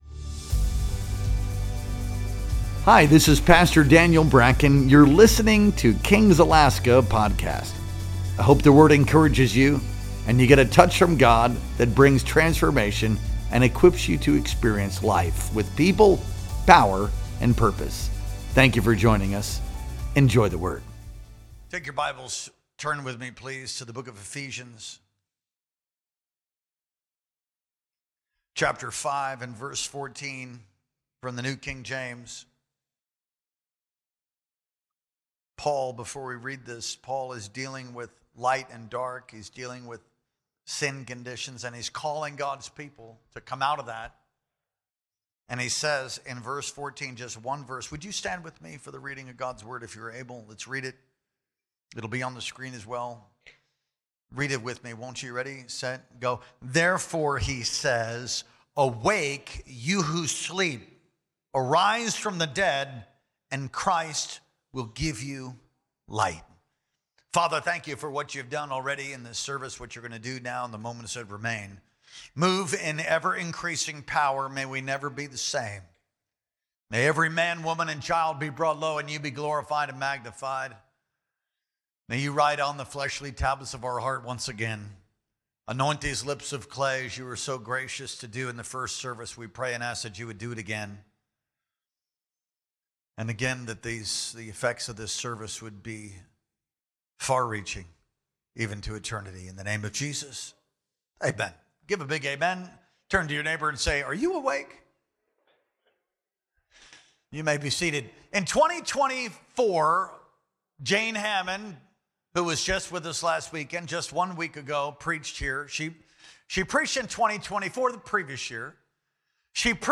Our Sunday Worship Experience streamed live on February 23rd, 2025.